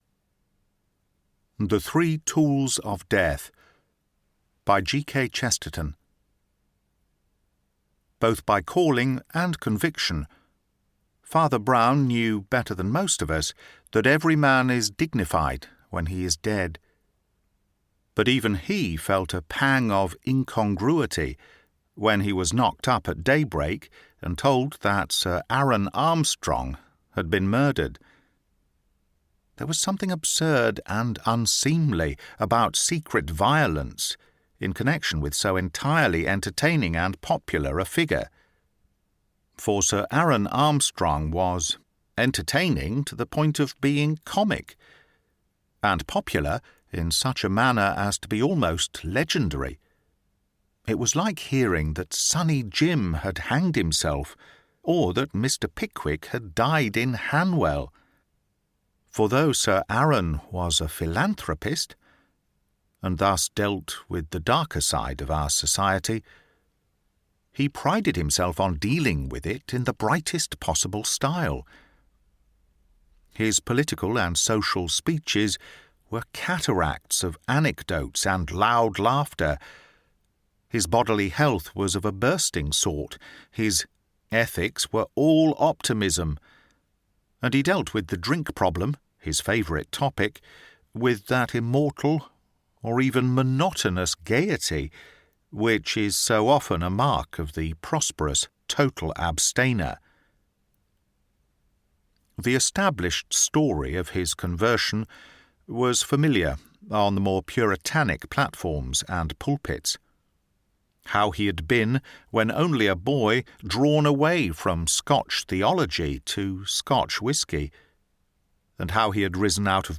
(Audiobook)